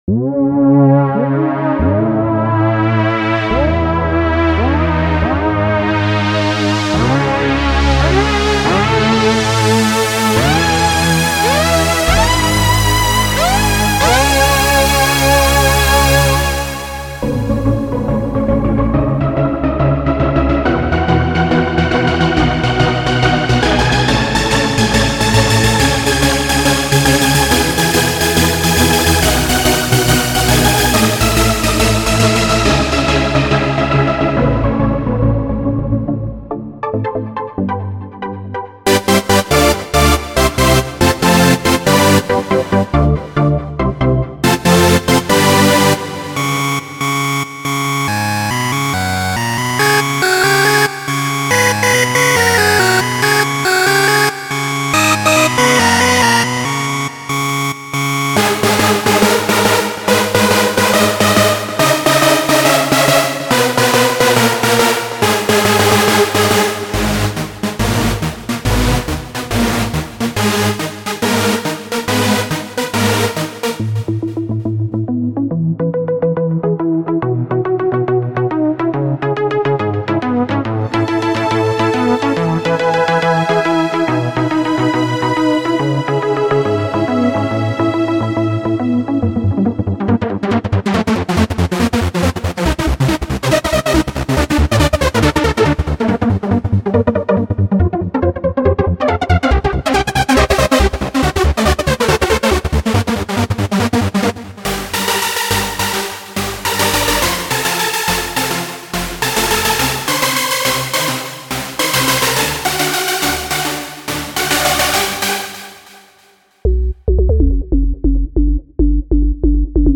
- All presets are velocity sensitive.
- All presets use the mod wheel (mostly just the filter cutoff and/or resonance)
vstsoundbanks_-_sylenth1_1.mp3